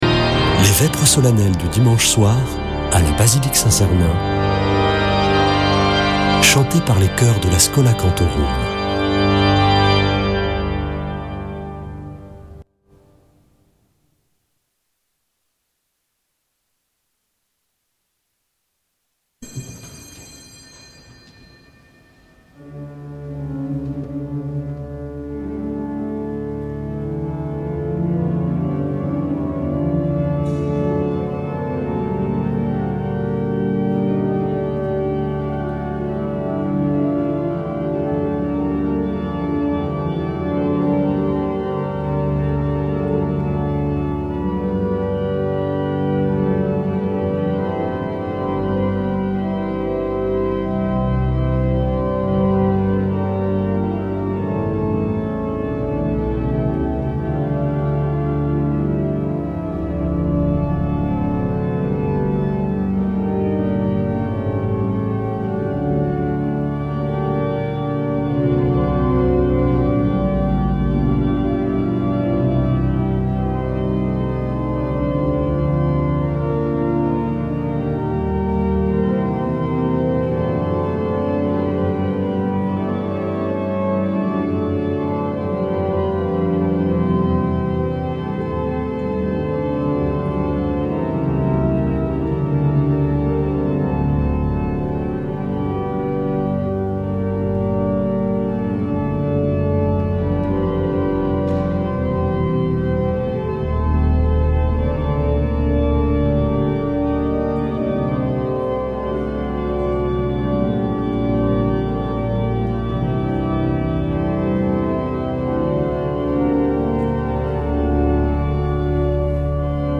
Vêpres de Saint Sernin du 02 mars
Une émission présentée par Schola Saint Sernin Chanteurs